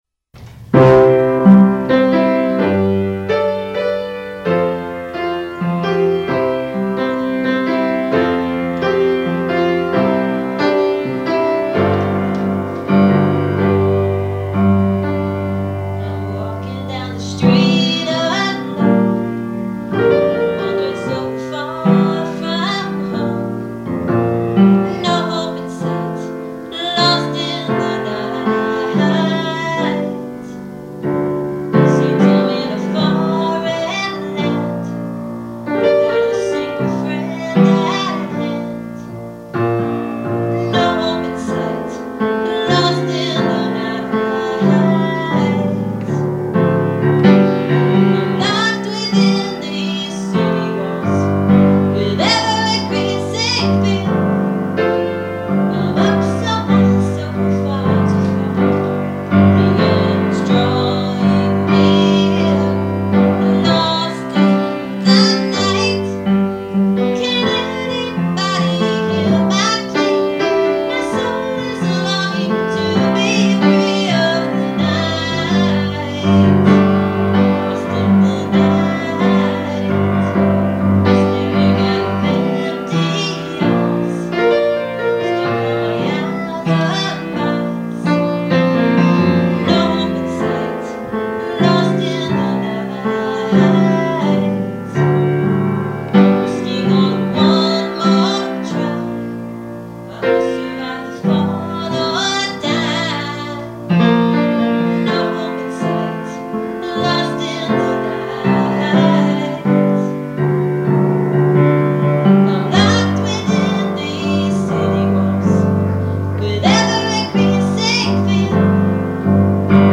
1986-unproduced rehearsal session